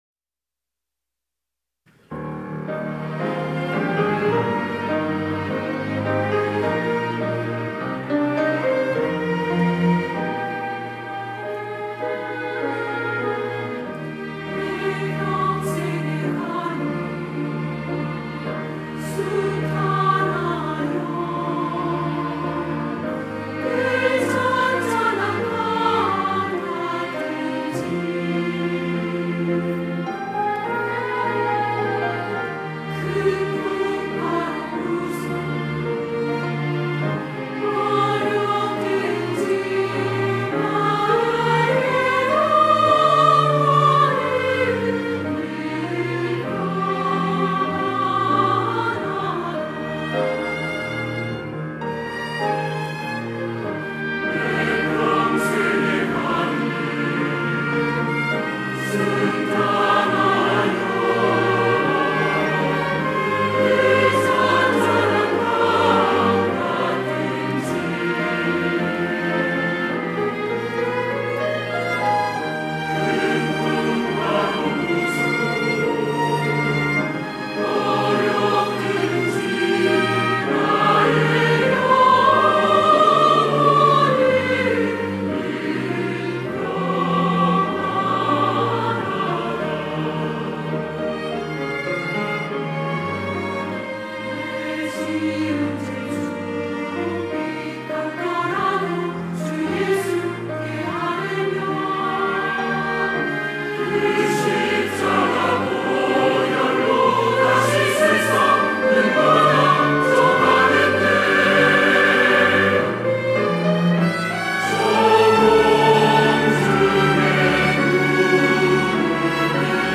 할렐루야(주일2부) - 내 평생에 가는 길
찬양대 할렐루야